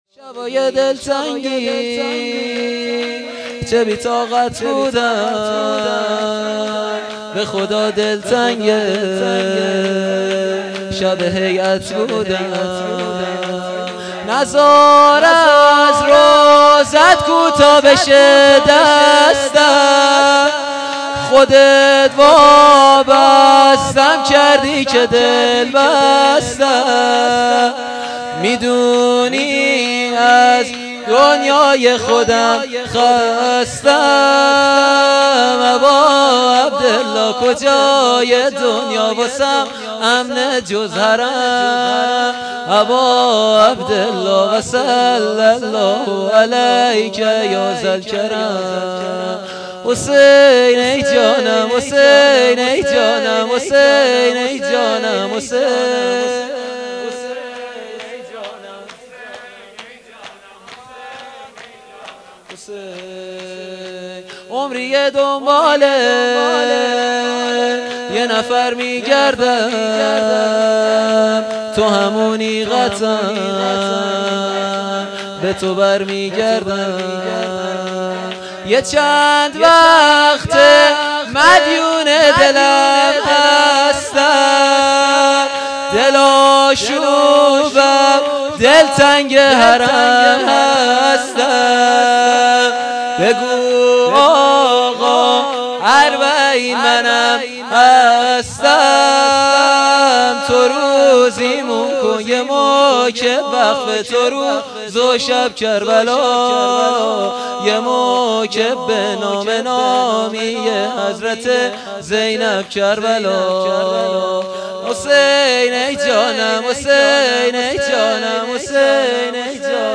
صوت هیئت هفتگی 1400/7/8